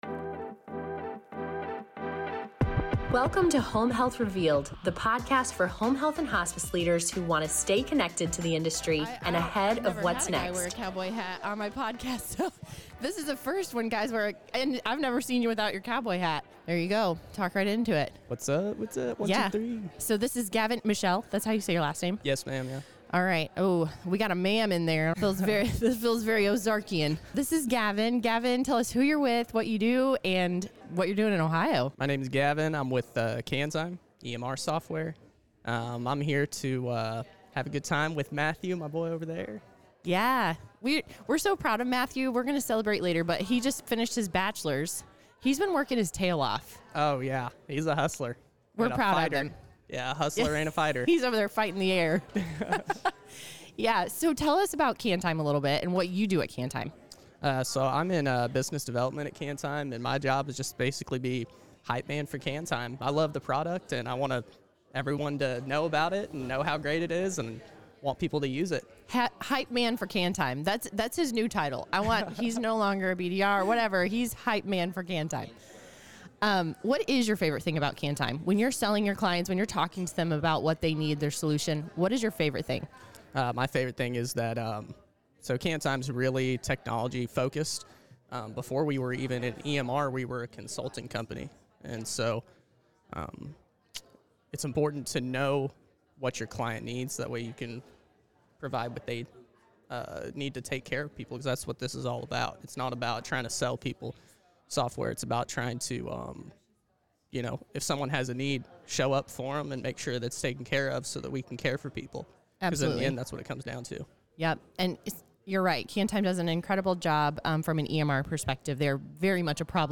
recorded live at the OCHCH Spring Financial Conference